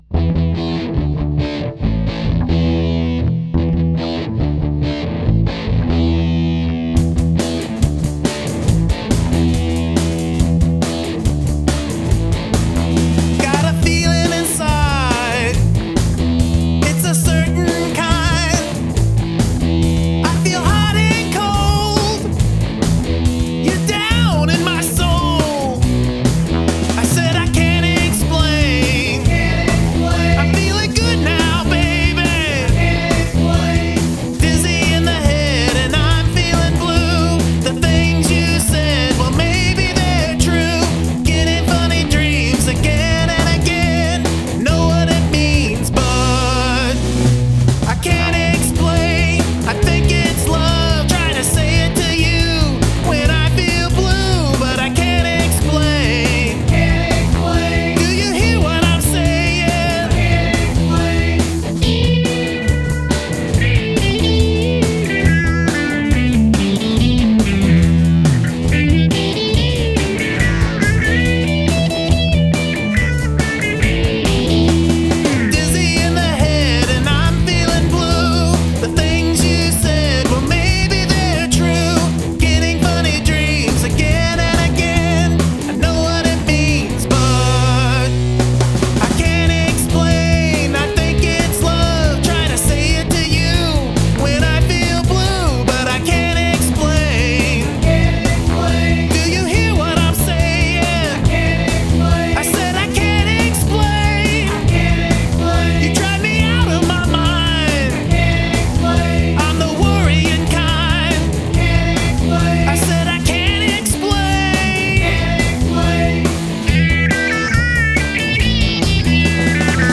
Recorded on my Boss BR-900
Track 2: Bass Guitar (my acoustic/electric DI)
Track 4: (1-3 virtual tracks) Backup Vox
Love the buzz.
Dude, great energy to this one, rocking cover all the way.
Rocking sound.